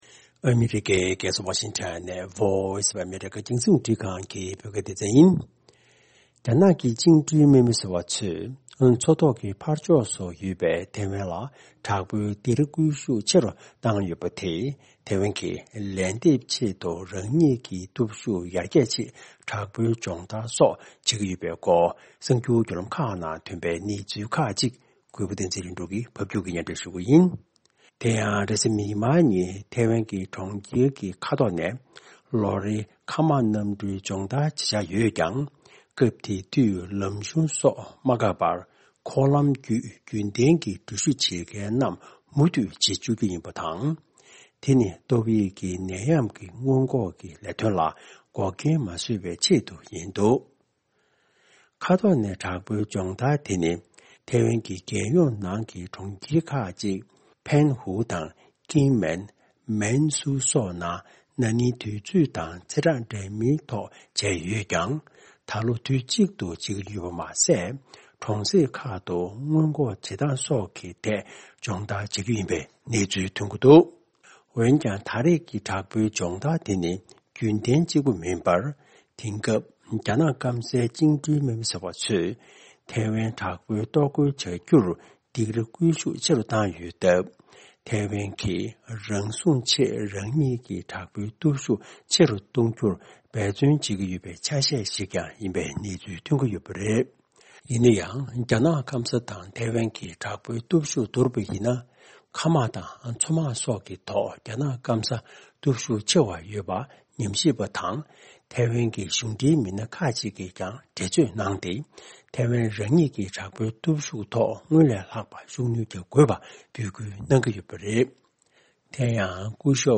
ཕབ་སྒྱུར་དང་སྙན་སྒྲོན་ཞུ་གནང་གི་རེད།